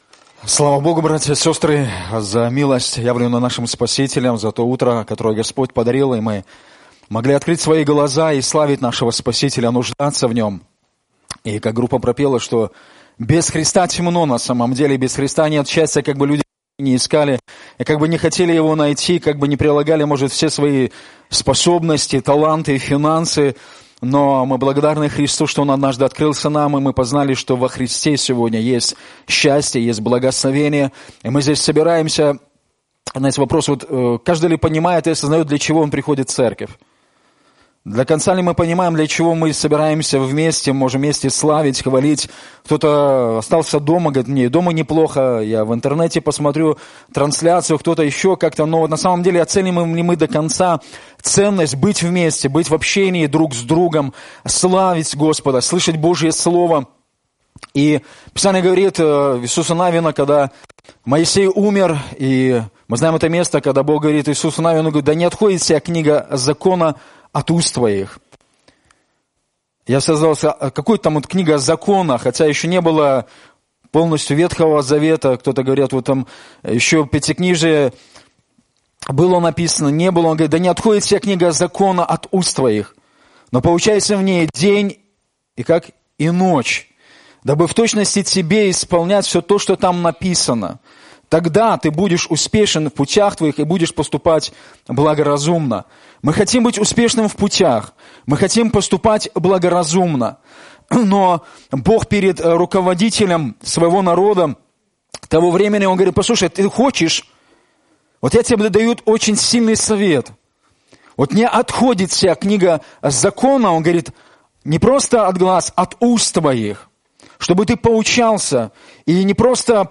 Пропаведзі